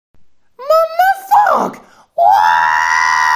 R2D2 en roue libre
r2d2-en-roue-libre.mp3